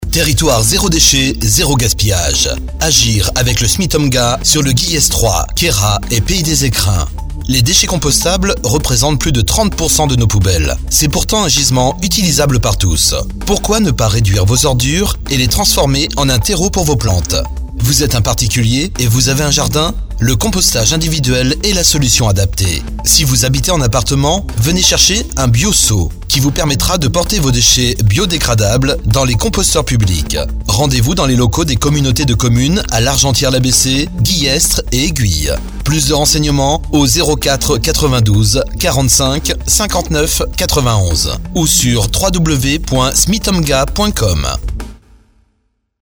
spot audio 45 secondes